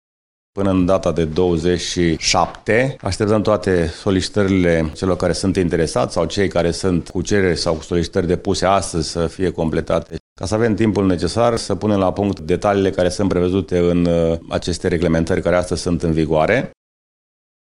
Primarul Municipiului Brașov, George Scripcaru: